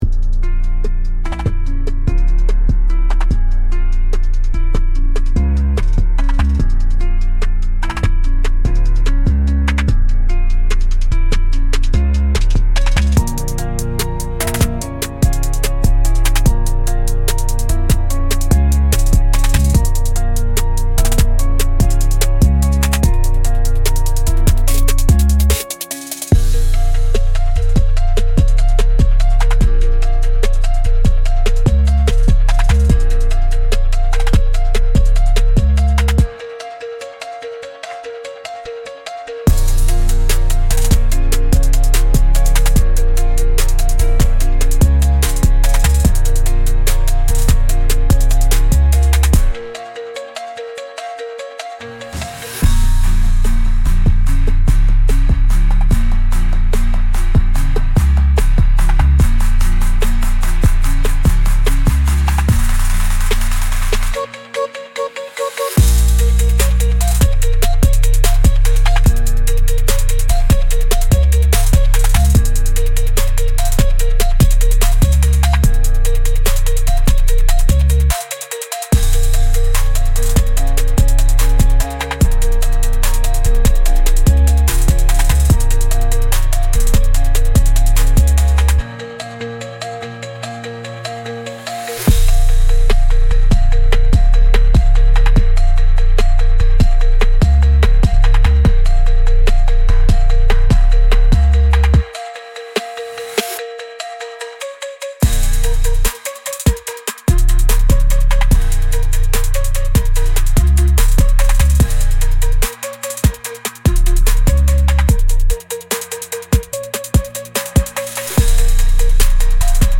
Instrumental - Chrono-Scales